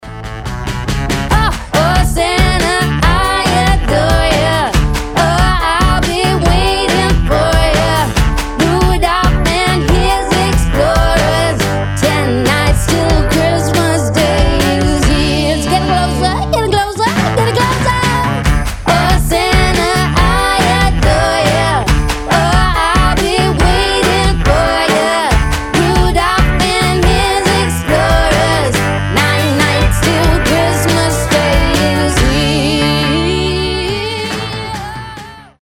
• Качество: 320, Stereo
праздничные
рождественские